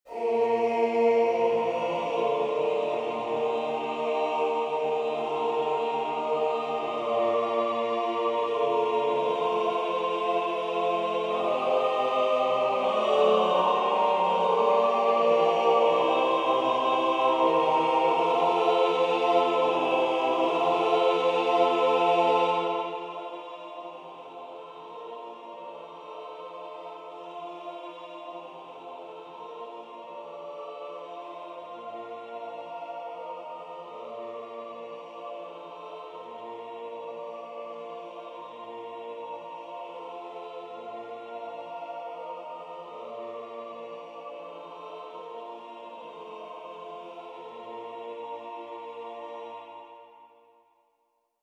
elektronisch